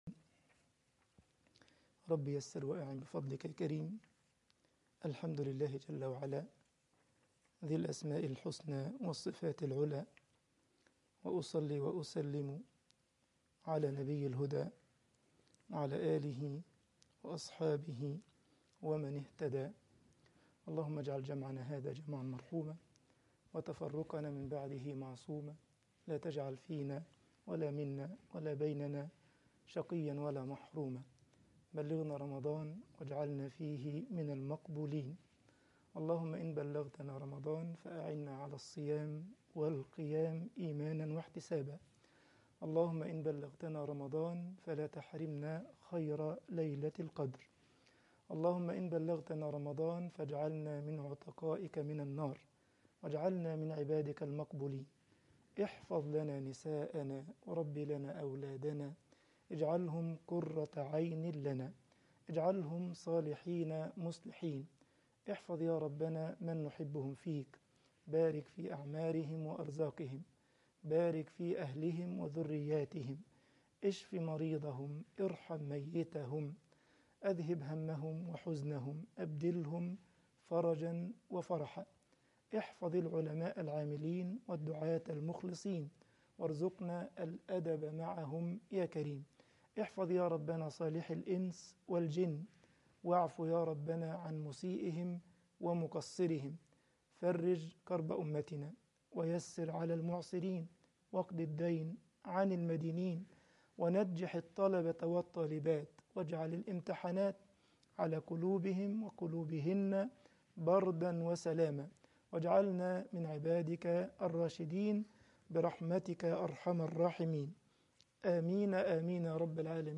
الدورة العلمية رقم 1 أحكام الصيام المحاضرة رقم 3